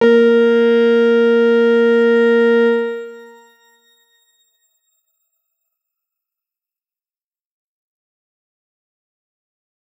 X_Grain-A#3-pp.wav